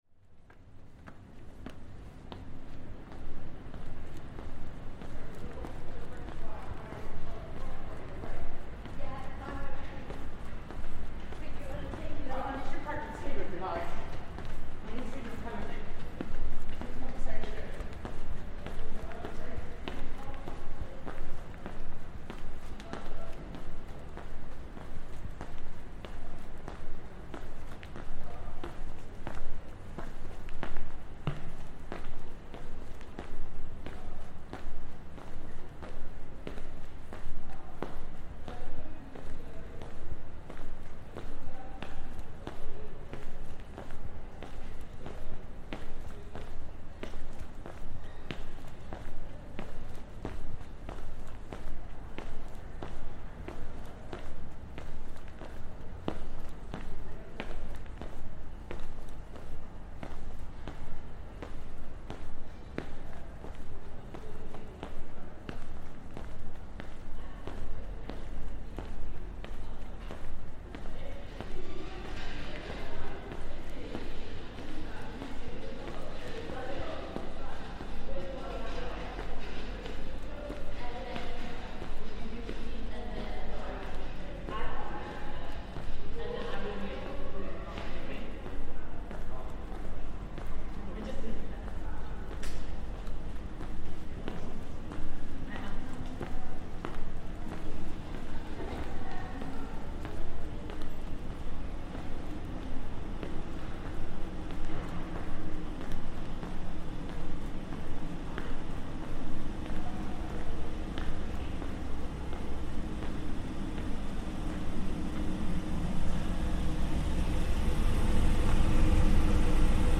In March 2025 we made a series of recordings in and around the Barbican Centre, with the idea of those sounds being folded back into the Observatory Station sound installation, so that the sounds of the Barbican itself become part of the stories being told by sound from around the world. This recording is a walkthrough of some of the exterior concrete tunnels and walkways that make up this brutalist masterpiece - footsteps, various mysterious drones, and the sounds of assorted passers-by.